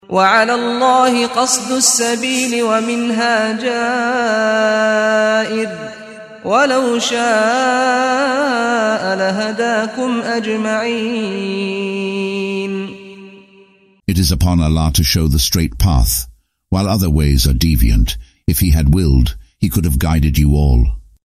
قراءة صوتية باللغة الإنجليزية لمعاني سورة النحل مقسمة بالآيات، مصحوبة بتلاوة القارئ سعد الغامدي.